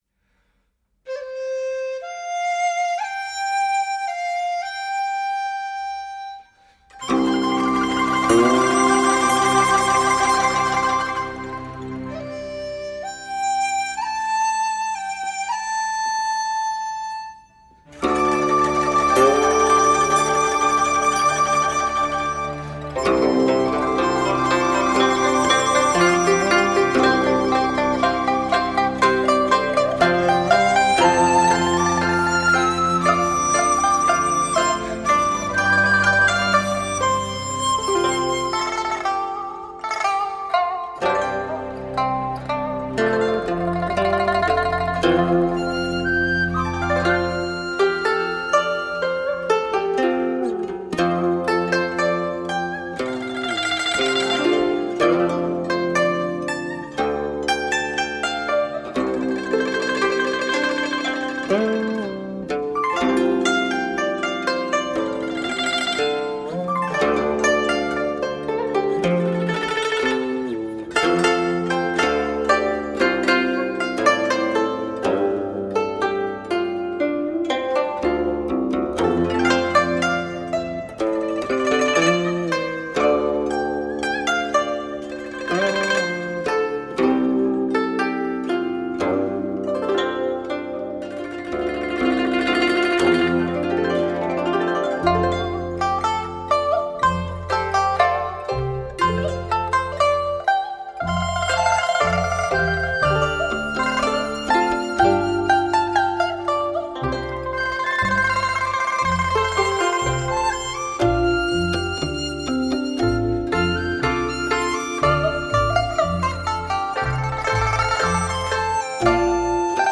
四重奏
四重奏如歌般的旋律
如清泉般自然流露浑然天成
烁烁生辉的红色魅力 永不淡退的艺术色彩 激情流淌 磅礴巨作